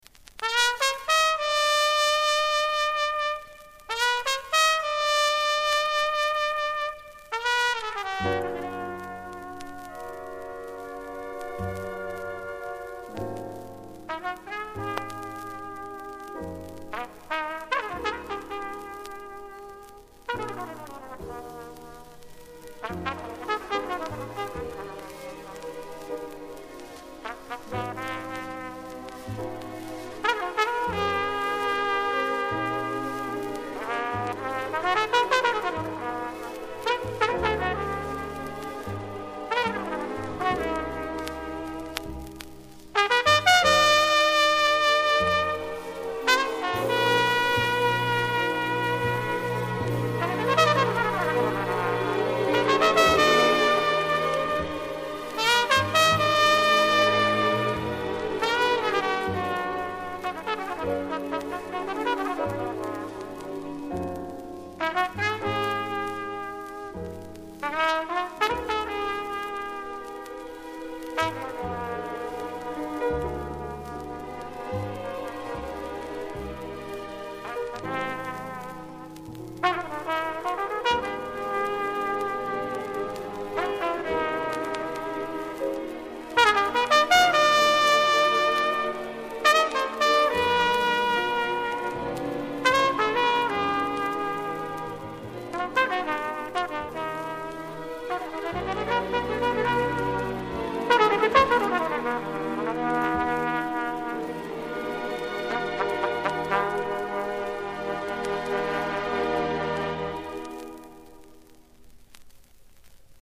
Еше один джазовый музыкант-трубач из Чехословакии